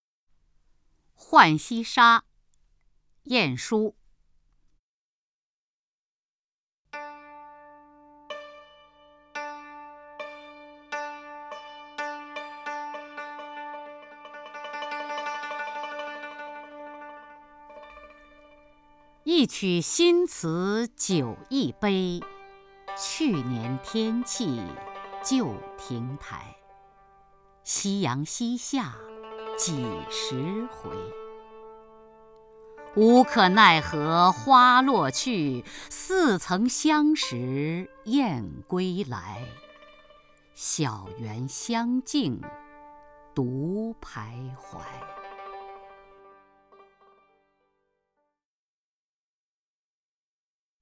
雅坤朗诵：《浣溪沙·一曲新词酒一杯》(（北宋）晏殊)　/ （北宋）晏殊
名家朗诵欣赏 雅坤 目录